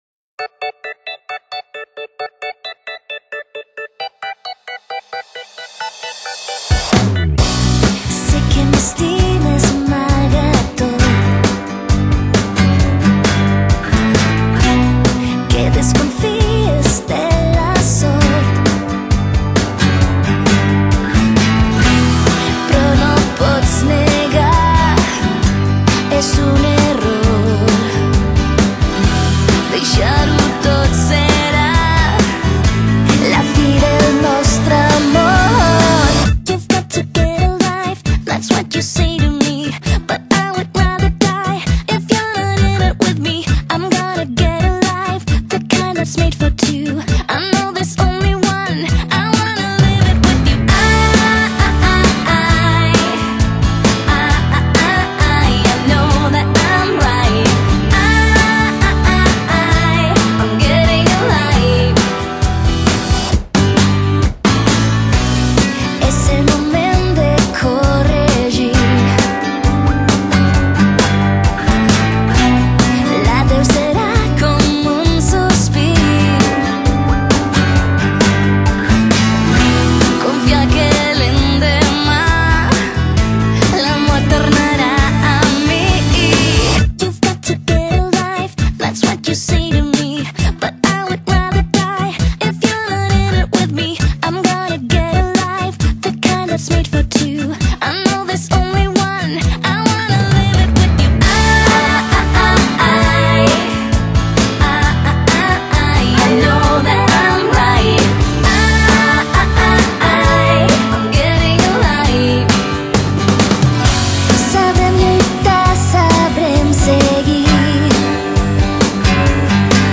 音乐风格：Pop / Rock / Dance / Folk etc